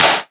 edm-clap-25.wav